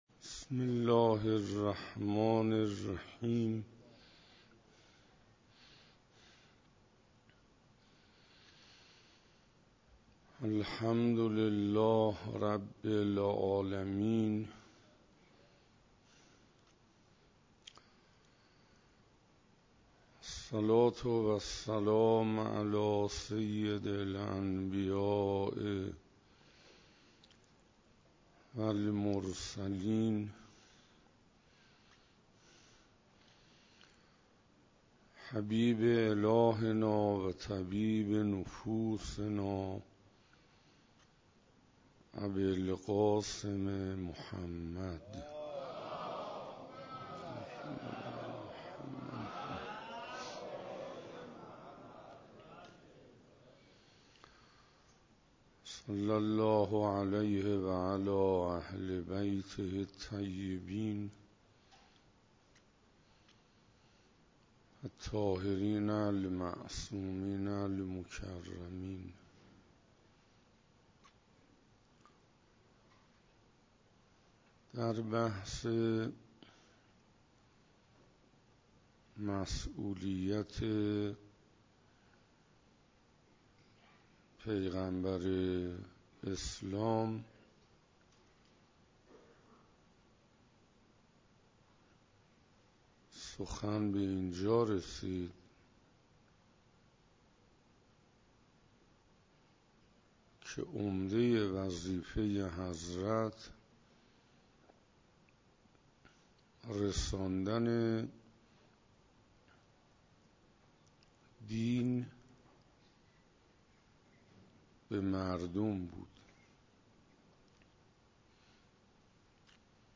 دهه سوم ماه رجب 97 - جلسه دهم -حسینیه همدانی ها - مسولیت پیامبر (ص)